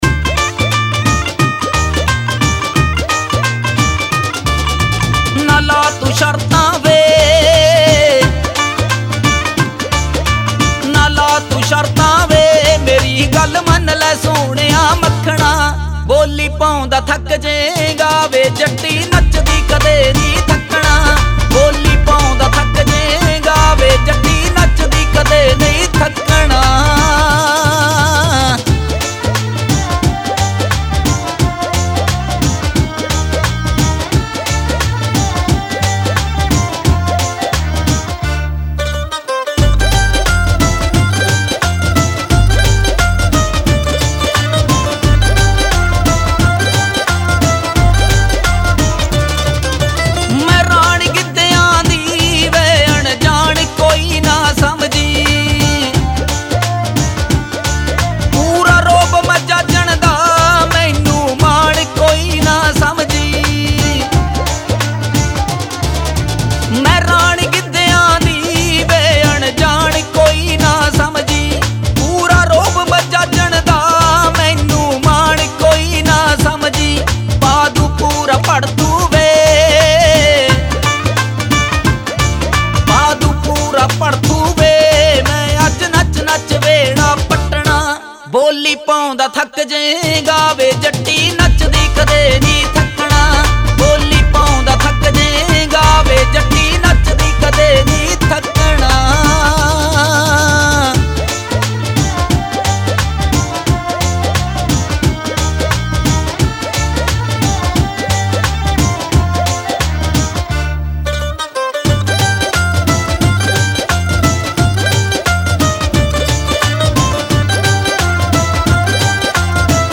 Punjabi Audio Songs